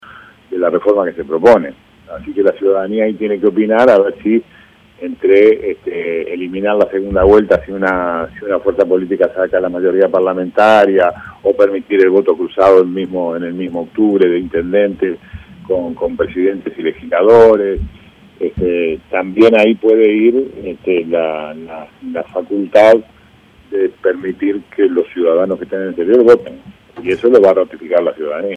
En diálogo con El Espectador, el canciller sostuvo que "hoy Uruguay tiene el triste papel de ser el único país de América del Sur que no tiene el voto para sus compatriotas que están en el exterior".